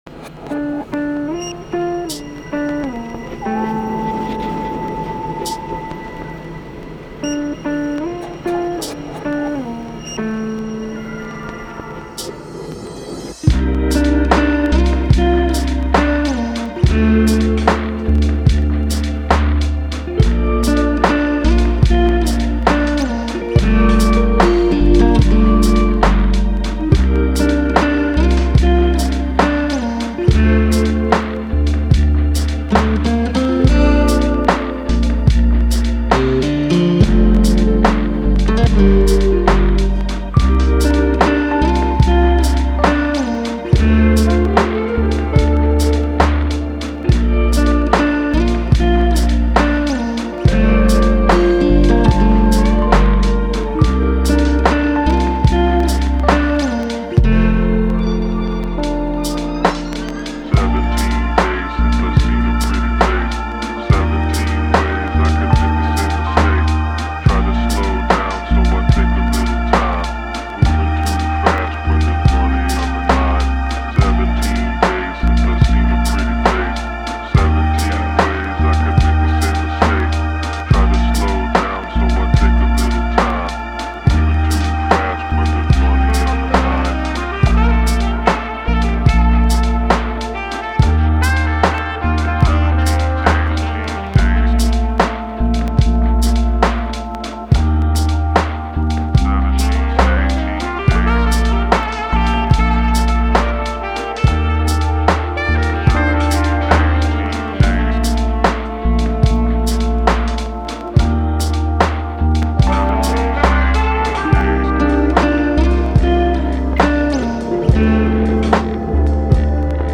An original lo-fi track composed in 33/32 time signature to create a natural "slop" effect. The harmony hovers between relative minor and major keys with ambient Las Vegas field recordings.